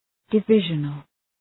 {dı’vıʒənəl}